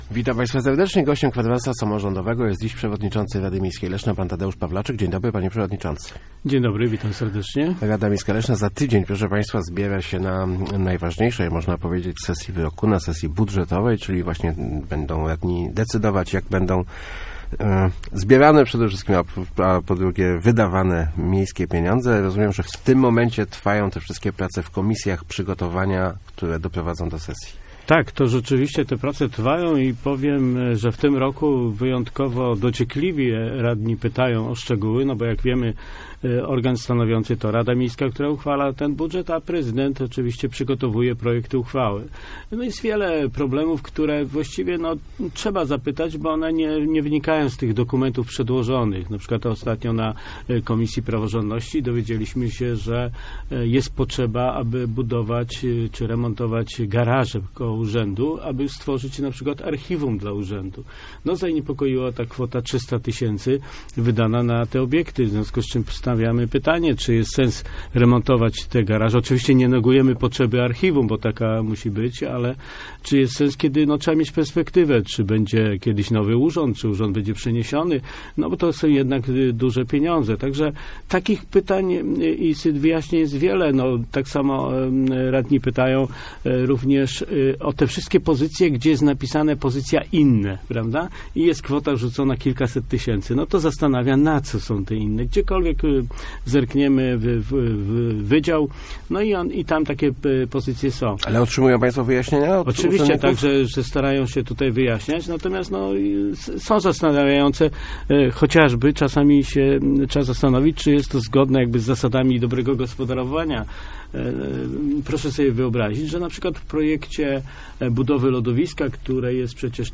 Gościem Kwadransa był Tadeusz Pawlaczyk, przewodniczący Rady Miejskiej Leszna.